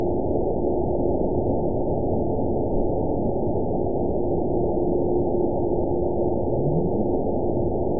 event 920119 date 02/24/24 time 00:37:16 GMT (1 year, 2 months ago) score 9.57 location TSS-AB03 detected by nrw target species NRW annotations +NRW Spectrogram: Frequency (kHz) vs. Time (s) audio not available .wav